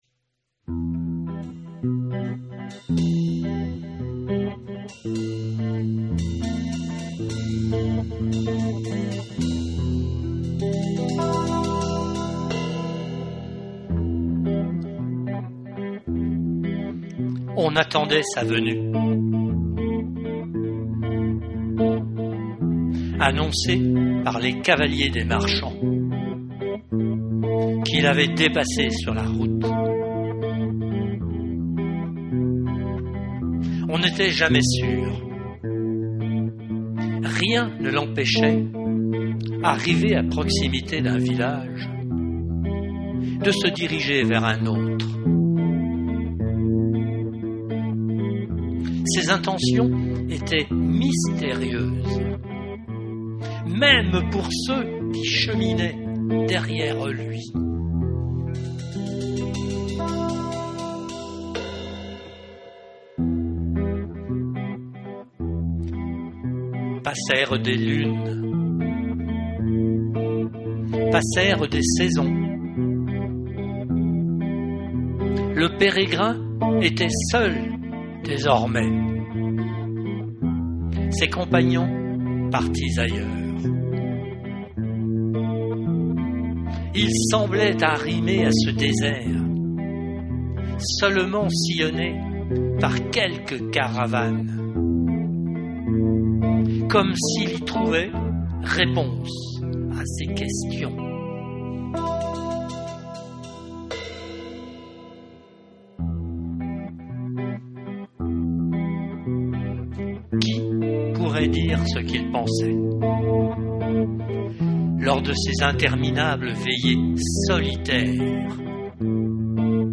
à la guitare
Ci-dessous quelques fragments du texte lu et mis en musique :